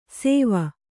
♪ sēva